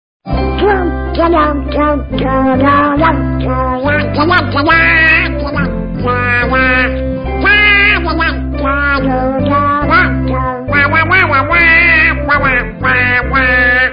تم للملم – نغمة كوميدية جداً